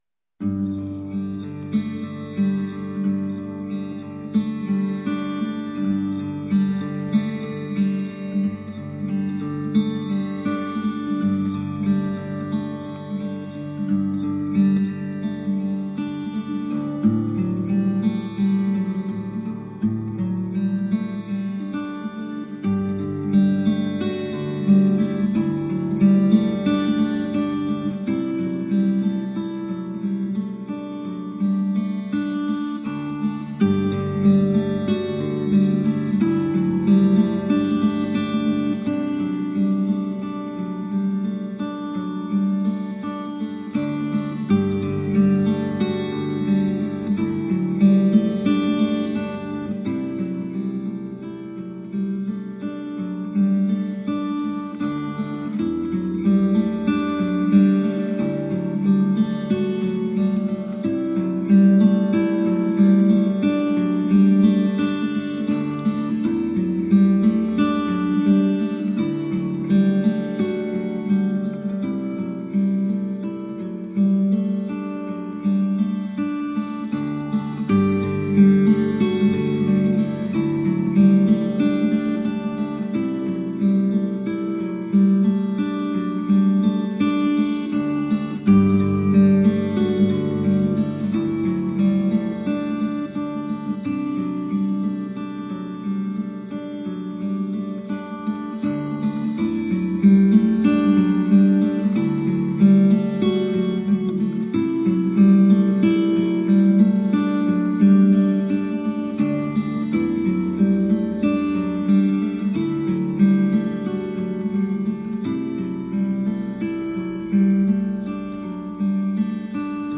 Acoustic new age and jazz guitar..
solo acoustic guitar pieces